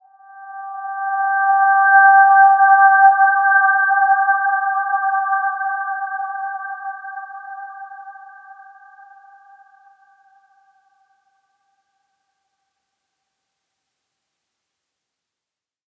Dreamy-Fifths-G5-mf.wav